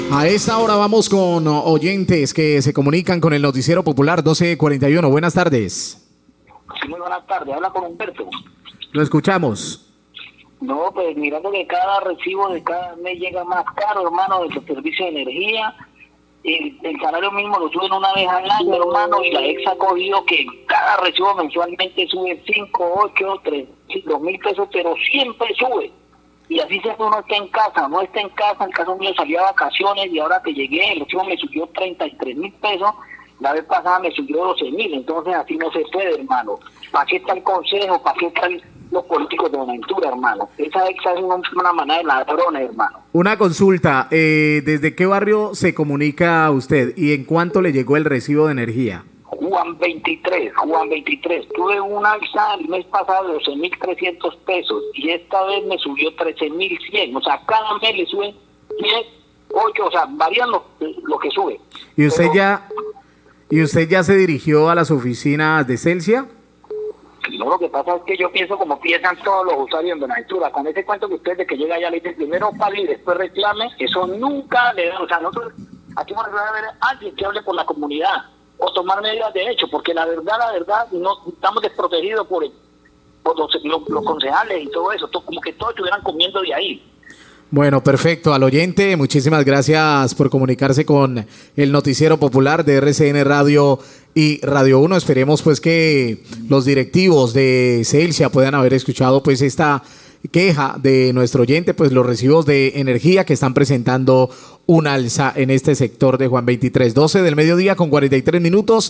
Queja de usurio barrio Juan XXIII por aumento en la factura de energía,
Radio
Oyente del barrio Juan XXIII se queja por el aumento el costo de factura del servicio de energía. Manifiesta que cada mes llega por un valor más elevado. Periodista lo invita a dirigirse a la oficina de la empresa, pero dice que primero se debe realizar el pago para presentar la queja y agrega que mejor es pasar a las vías de hecho.